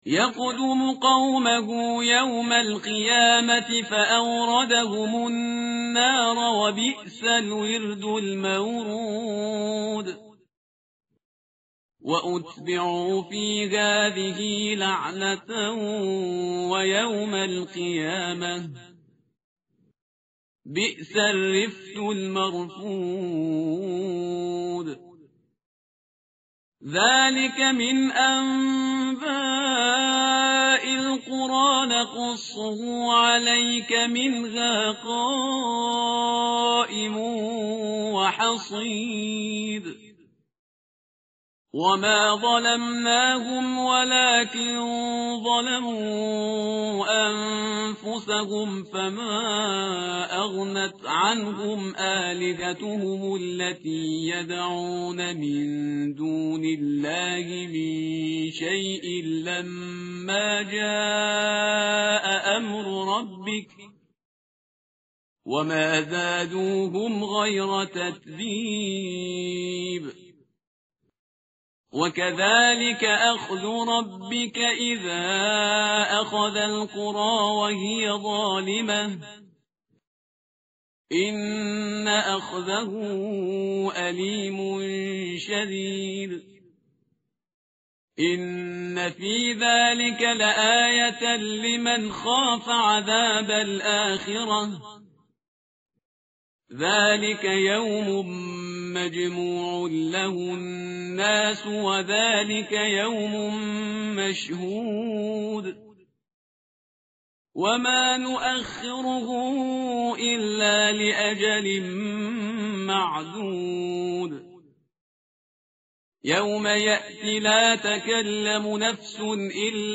tartil_parhizgar_page_233.mp3